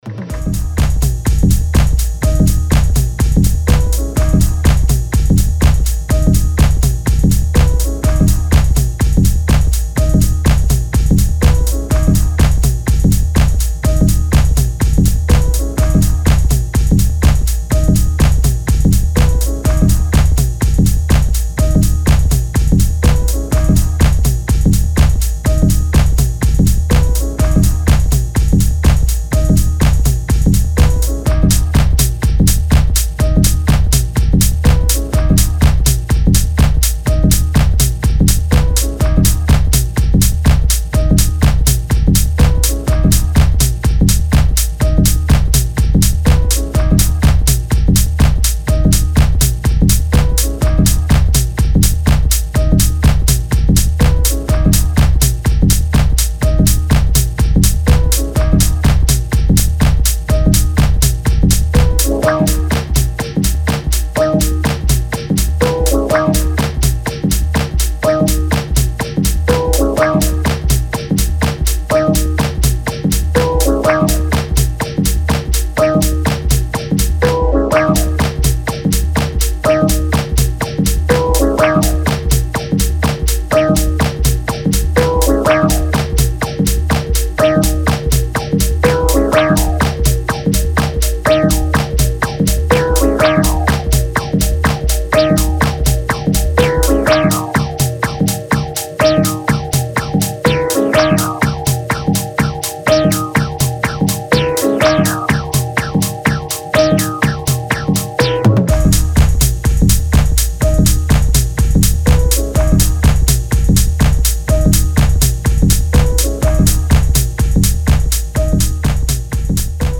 Style: Techno